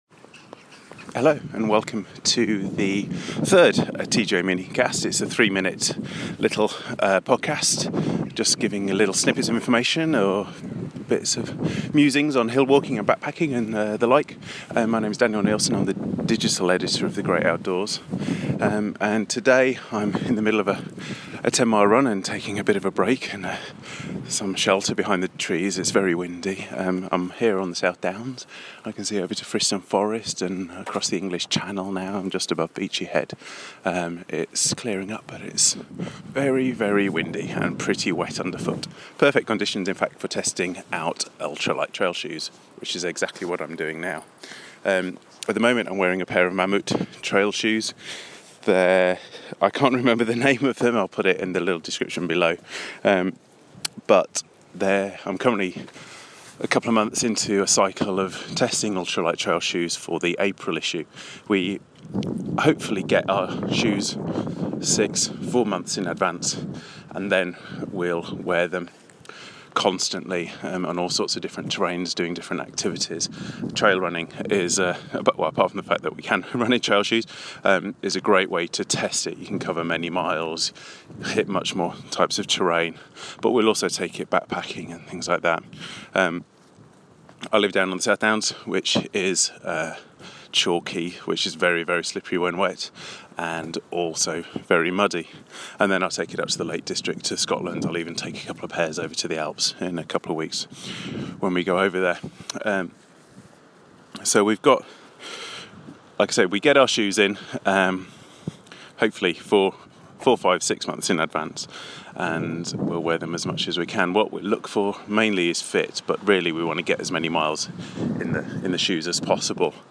Out on the trail testing ultralight trail running shoes. This is a quick and not particularly cohesive look into how we test trail running shoes at The Great Outdoors magazine. part 1.